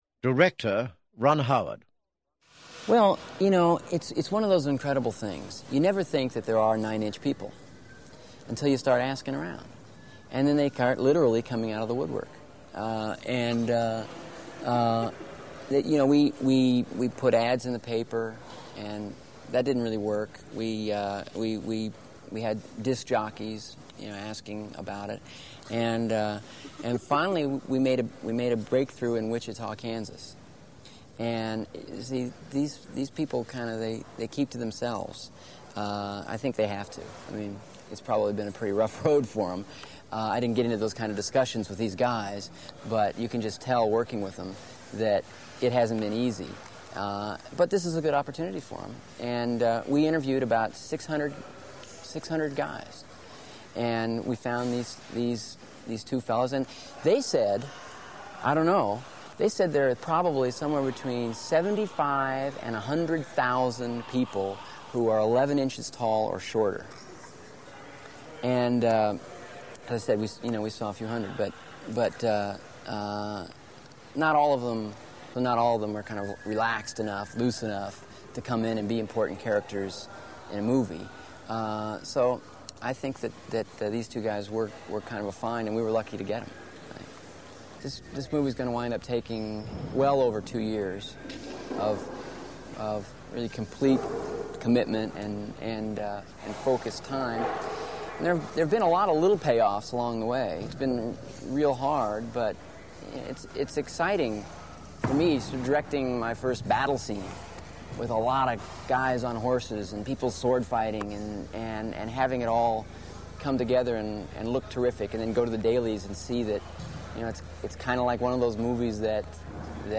Ron Howard interview MP3 From the Story of Willow album.